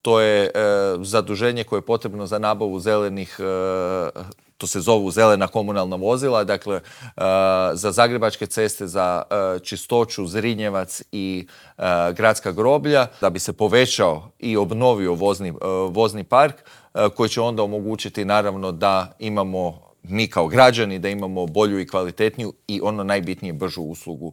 O ovim i drugim pitanjima smo u Intervjuu tjedna Media servisa razgovarali s predsjednikom zagrebačke Gradske skupštine, Matejem Mišićem.